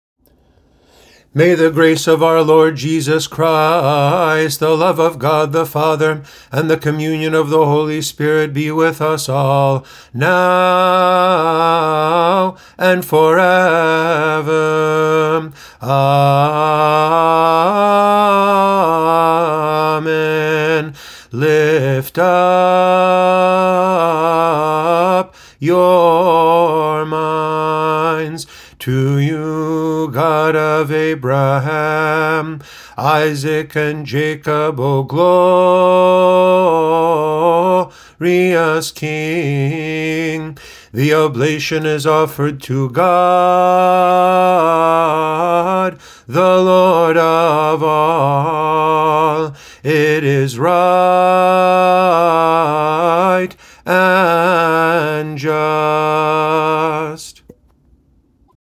They’re set to the traditional melodies, but in English, with close attention given to the stress of the syllables so it sounds as not-awkward as possible.
Please excuse the quality of my voice.